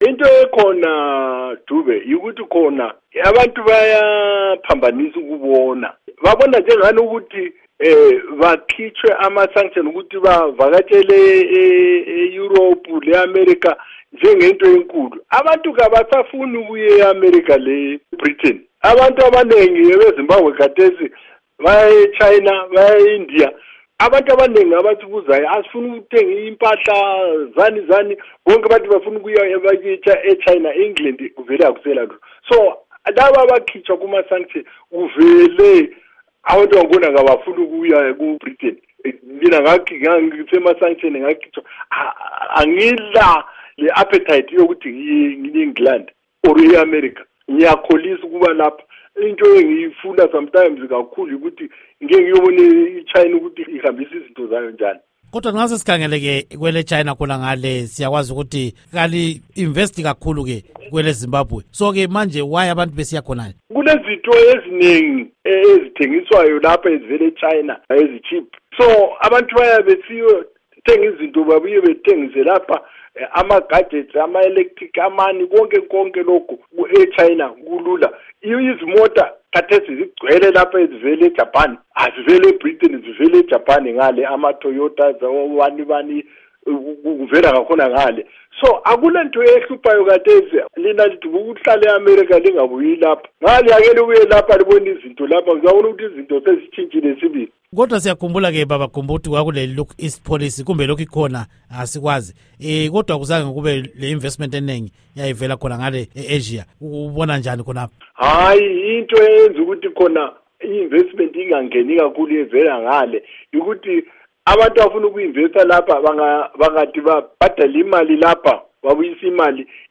Ingxoxo Esiyenze LoMnu. Rugare Gumbo